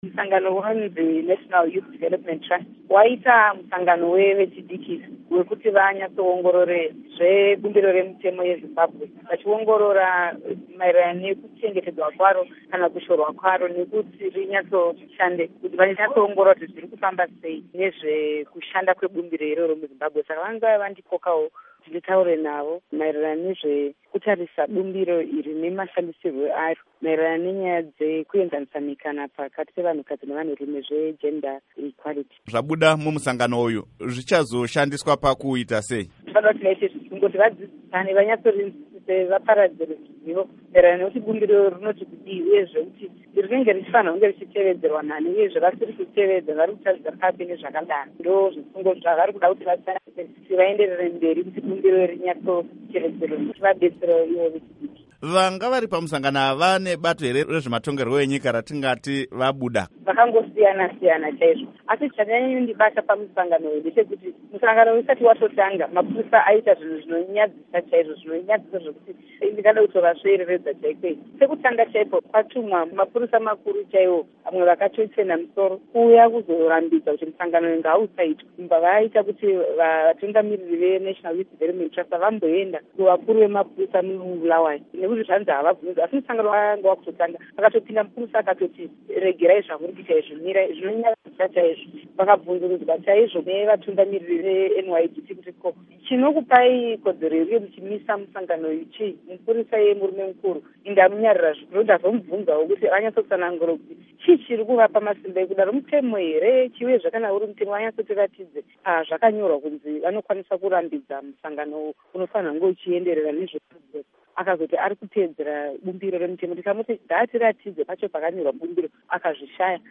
Hurukuro naMuzvare Jessie Majome